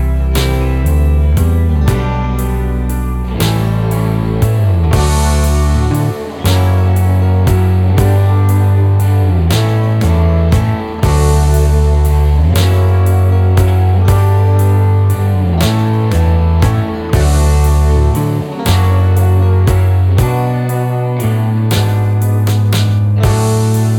no Backing Vocals Rock 4:10 Buy £1.50